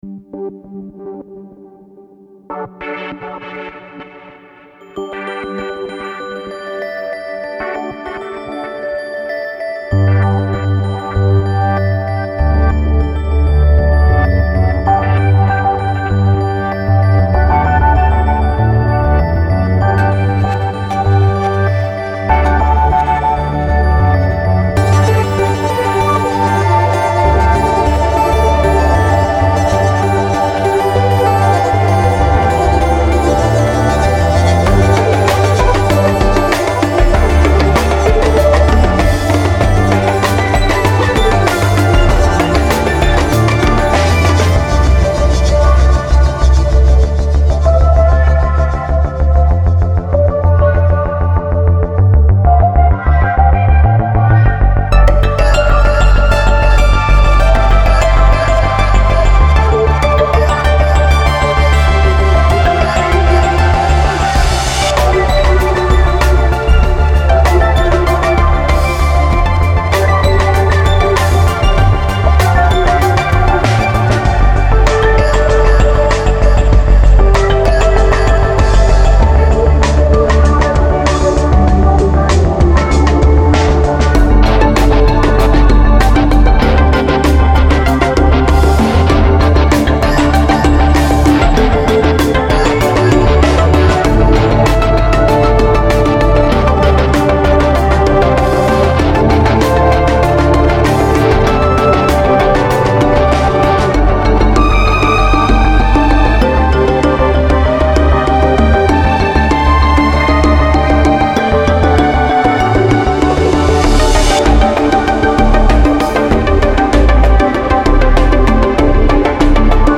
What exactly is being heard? Genre: Psybient.